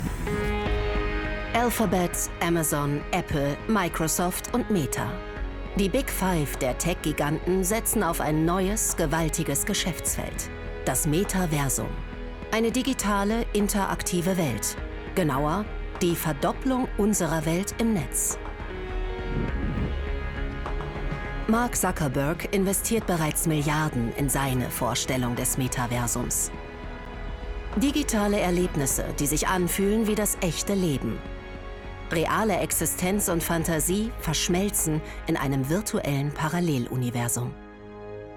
Kommentar (3Sat)